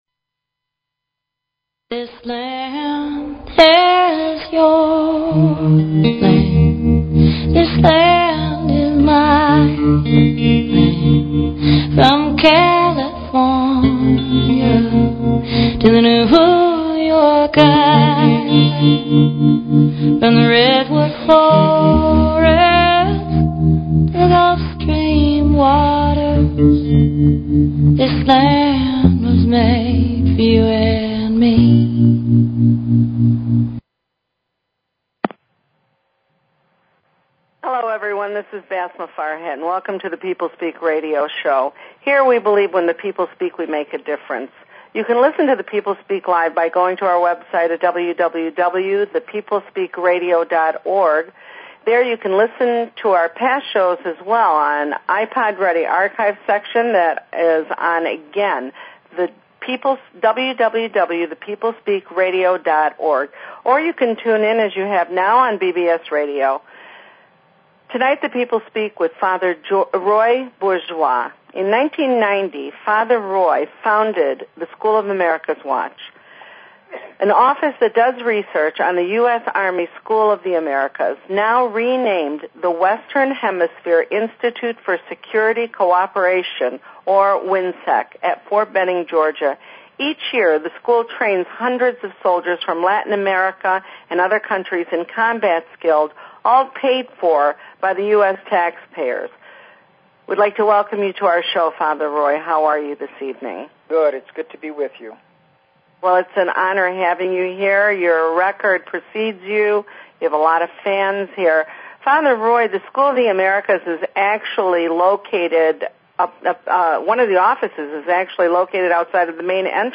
Talk Show Episode, Audio Podcast, The_People_Speak and Courtesy of BBS Radio on , show guests , about , categorized as News,Politics & Government,Religion,Society and Culture
Guest, Roy Bourgeois